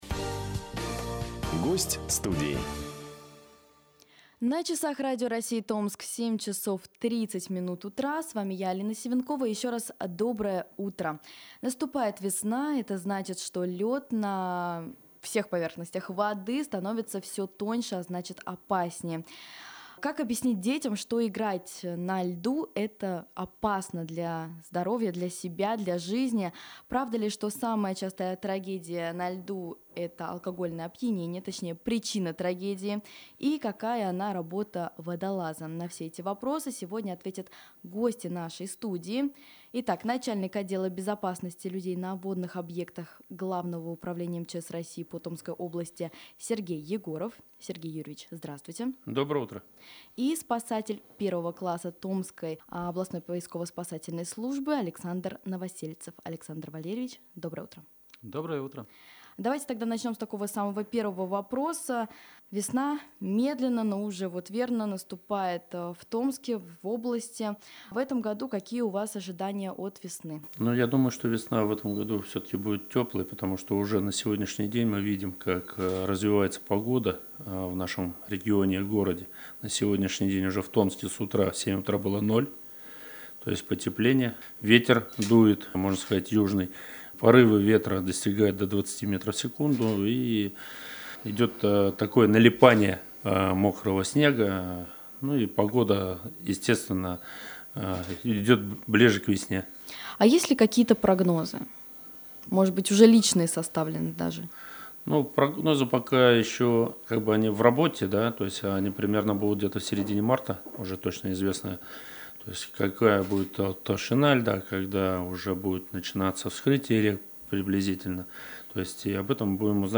Безопасность на водоемах в весенний период - Интервью - Главное управление МЧС России по Томской области